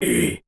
Male_Grunt_Hit_15.wav